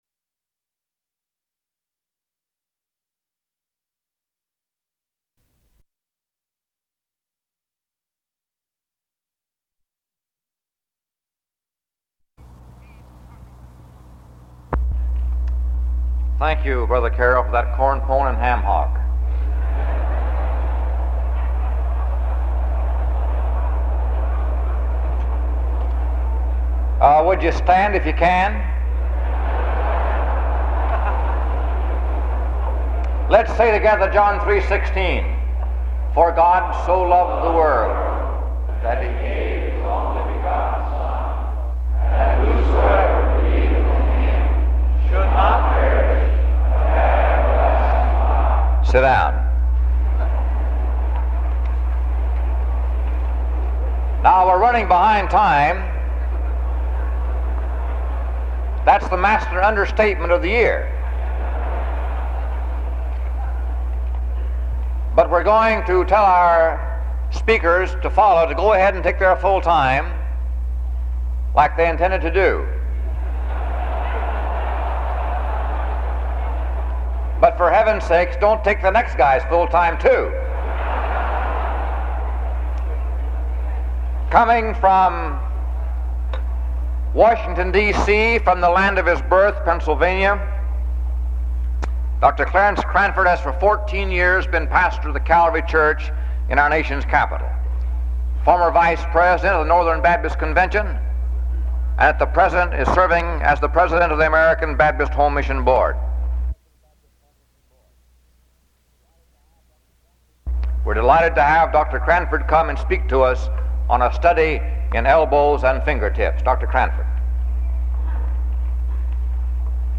The 1956 Pastor’s Conference was held May 28-29, 1956, at Music Hall in Kansas City, Missouri.